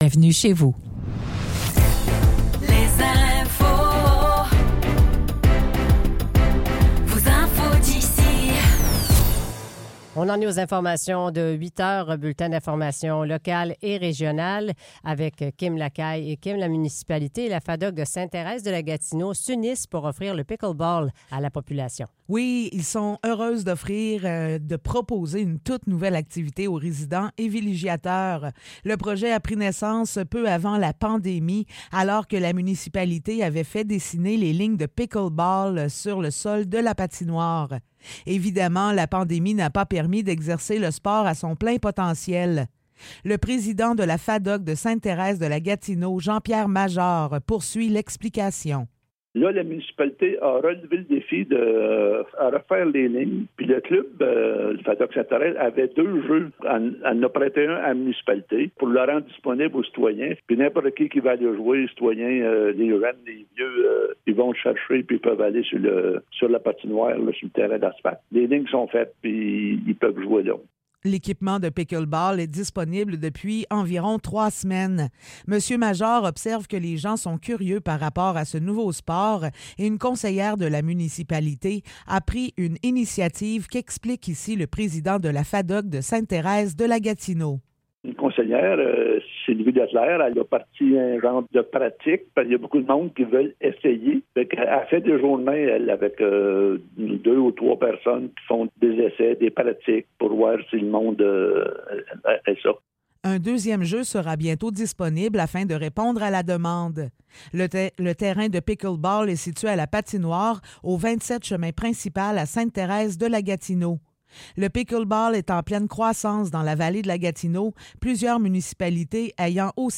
Nouvelles locales - 2 août 2024 - 8h